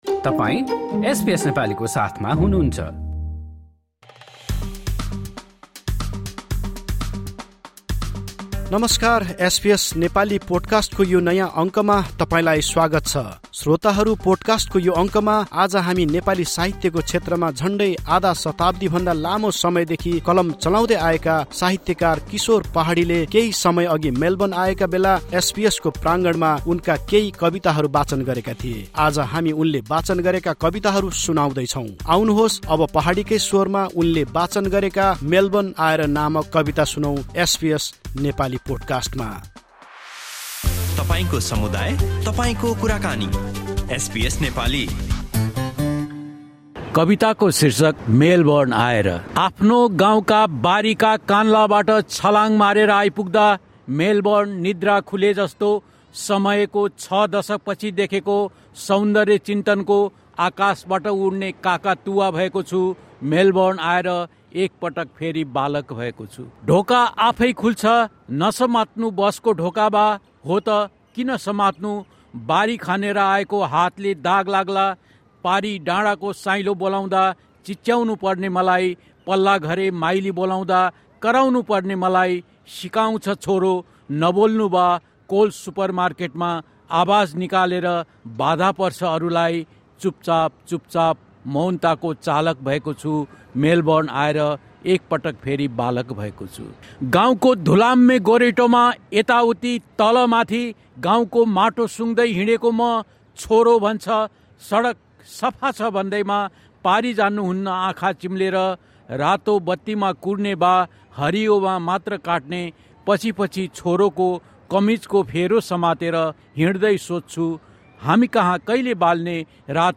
recited some of his poems at the Federation Square, Melbourne.